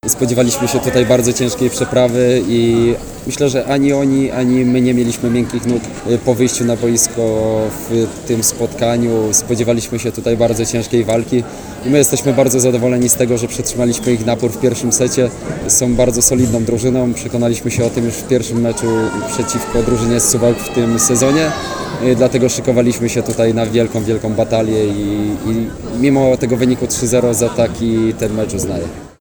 O krótki komentarz poprosiliśmy również Pawła Zatorskiego, doświadczonego libero aktualnych mistrzów Polski, reprezentanta kraju i dwukrotnego mistrza świata.